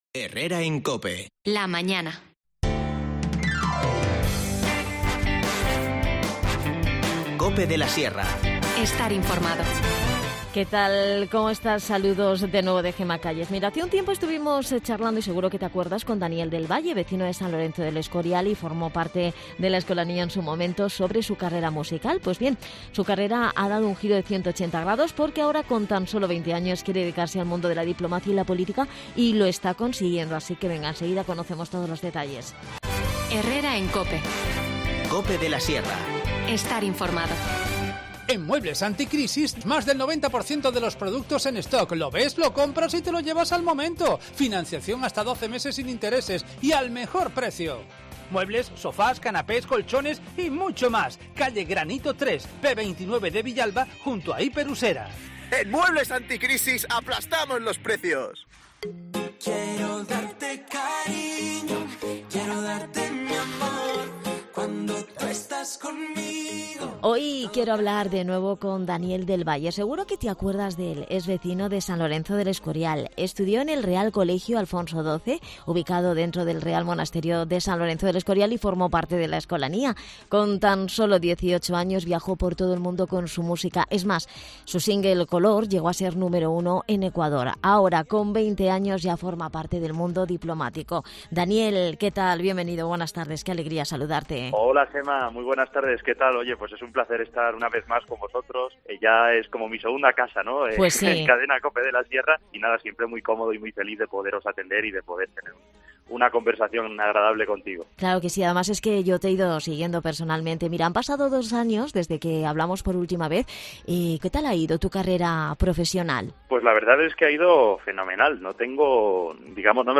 Charlamos con él en el programa sobre esta nueva experiencia profesional.